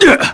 Nicx-Vox_Damage_kr_02.wav